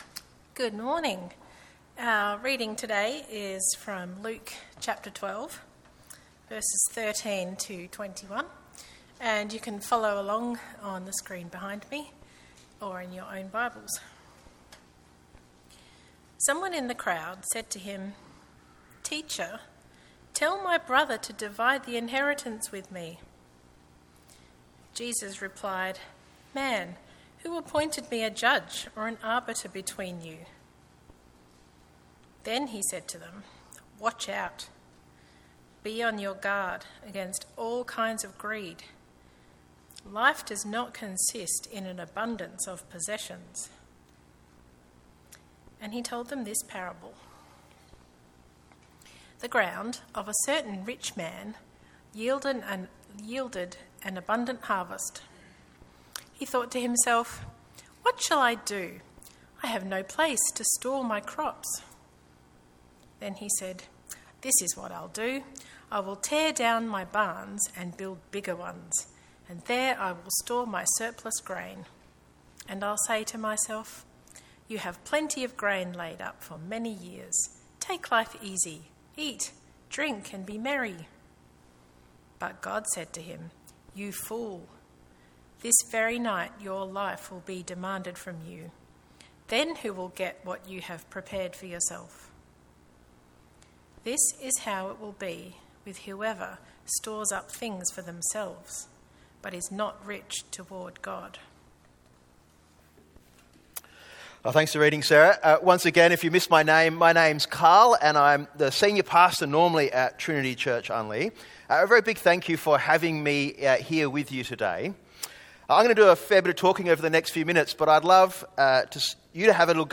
Play Rate Listened List Bookmark Get this podcast via API From The Podcast The sermon podcast for Trinity Church Colonel Light Gardens, a Trinity Network Church located in South Australia.